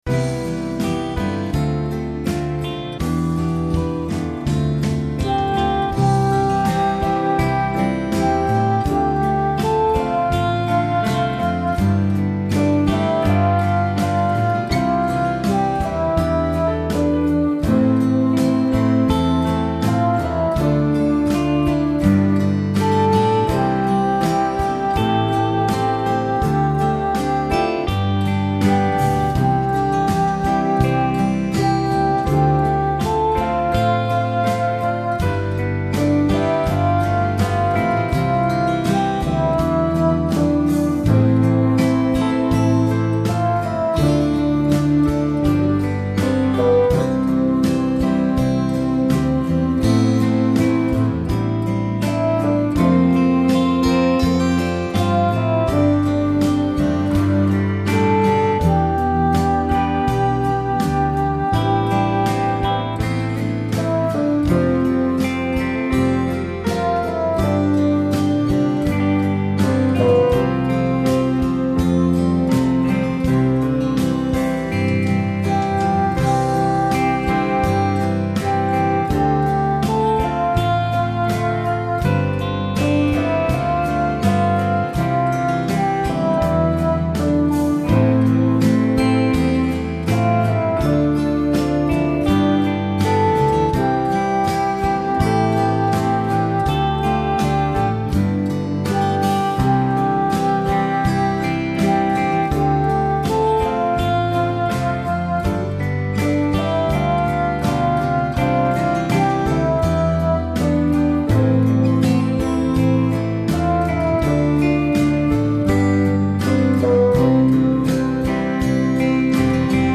My backing is at 82bpm.